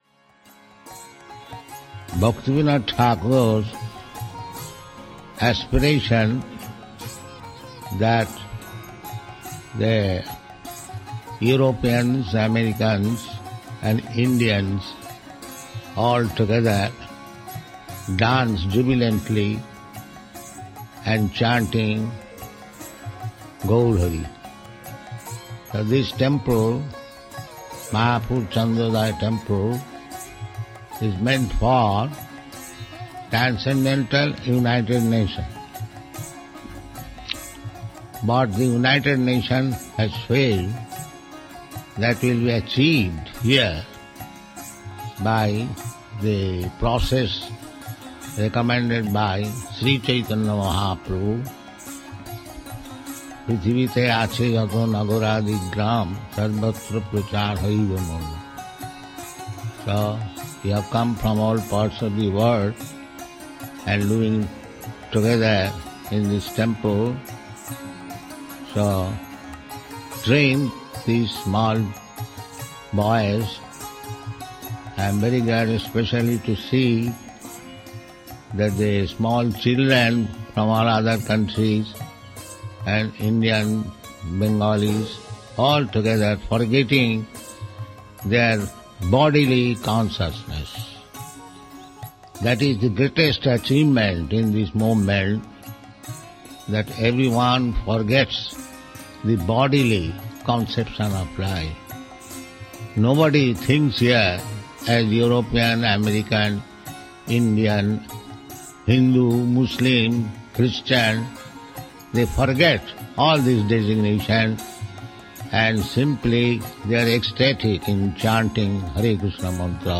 (750330 - Lecture CC Adi 01.06 - Mayapur)